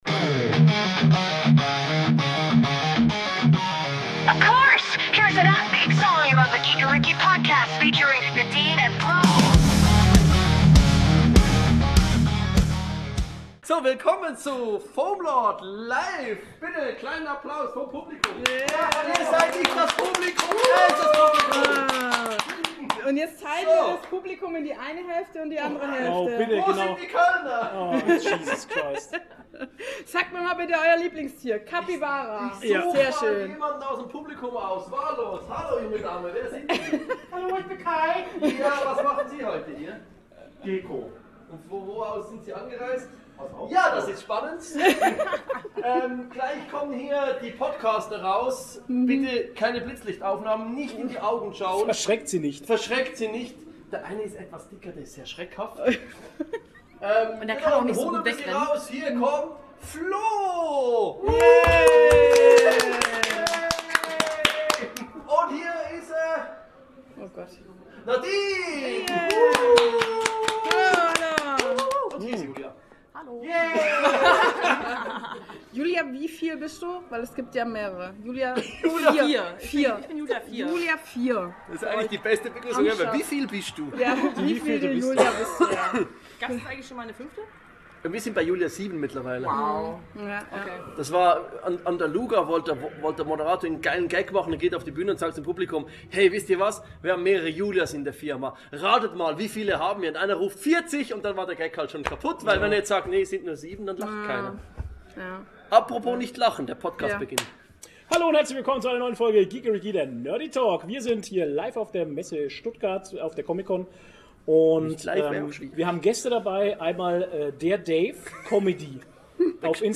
Diesmal melden wir uns live von der Comic Con Stuttgart!
Locker, ehrlich und mit jeder Menge Con-Feeling – perfekt zum Nebenbei-Hören.